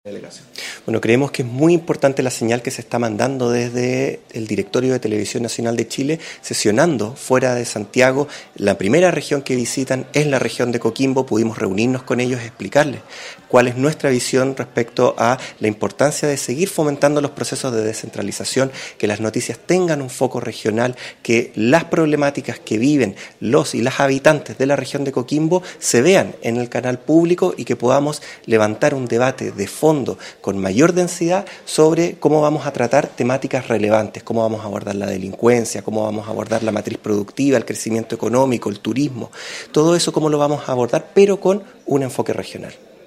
DIRECTORIO-TVN-Delegado-Presidencial-Ruben-Quezada.mp3